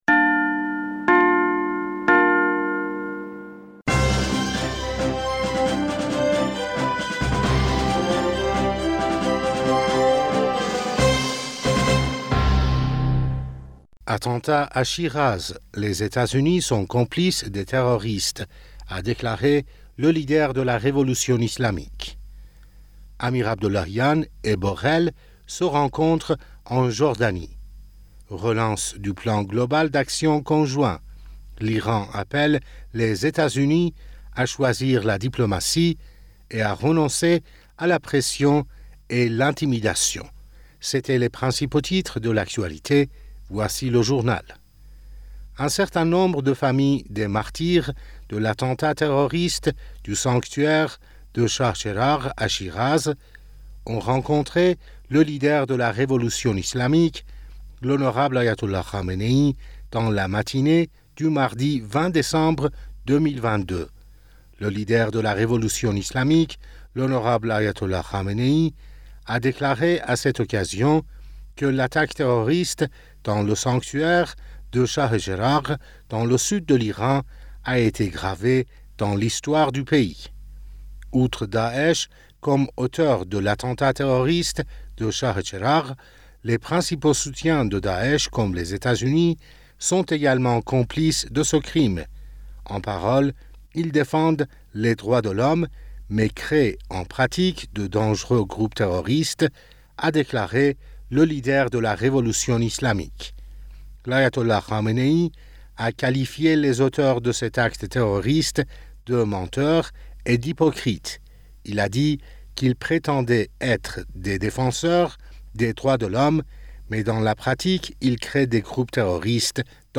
Bulletin d'information du 20 Décembre